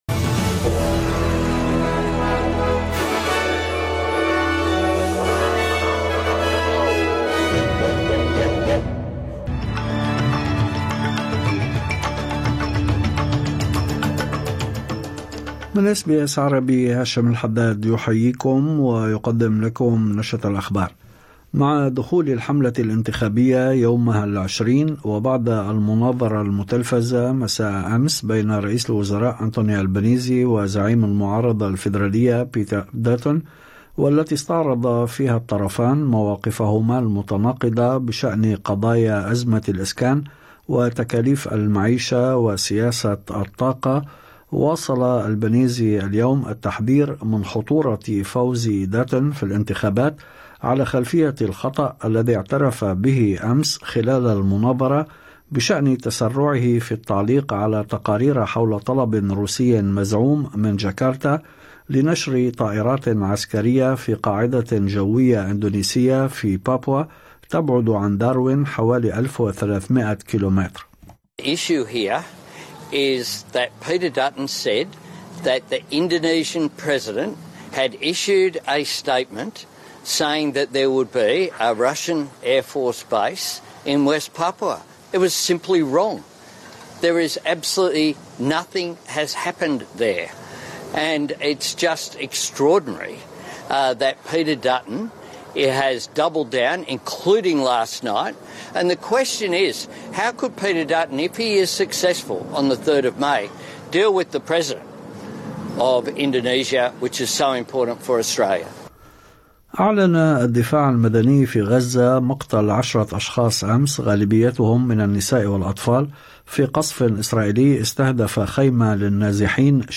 نشرة أخبار الظهيرة 17/04/2025